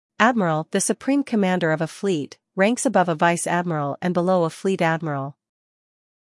英音/ ˈædmərəl / 美音/ ˈædmərəl /